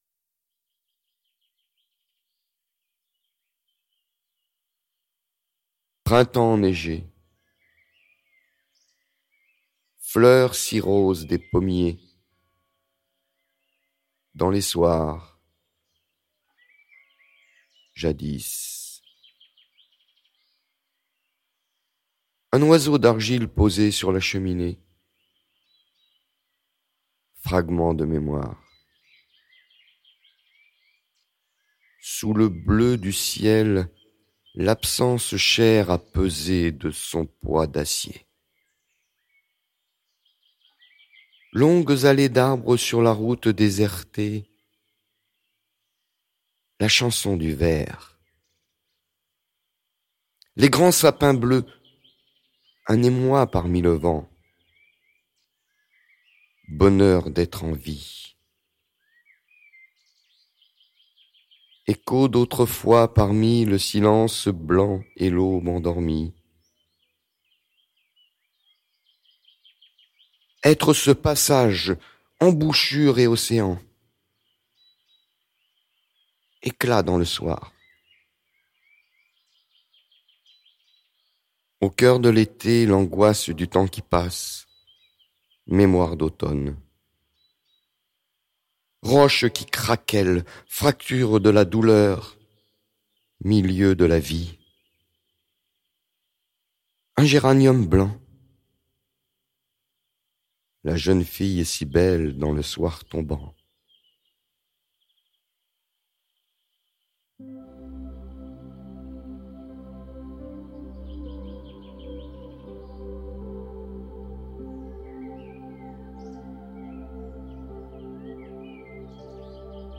Temps Immobiles - cherbourg - musiques d'accompagnement de spectacles (poesies, theatre et chansons) produites à cherbourg
Haï-kaï de Silvaine ARABO